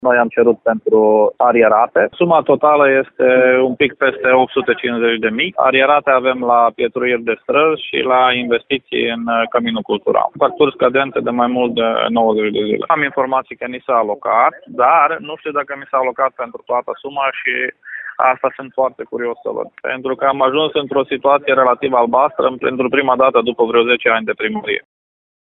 Și ultima localitate din vestul țării care primit alocări financiare  pentru plata datoriilor este Dudeștii Vechi, care conform primarului Gheorghe Nacov a solicitat peste 800.000 de lei.
primar-dudesti.mp3